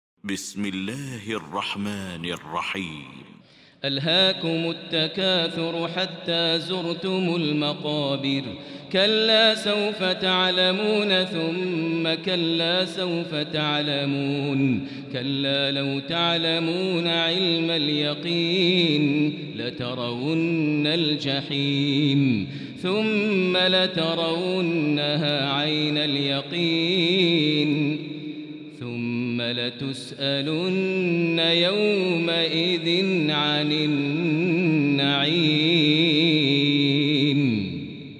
المكان: المسجد الحرام الشيخ: فضيلة الشيخ ماهر المعيقلي فضيلة الشيخ ماهر المعيقلي التكاثر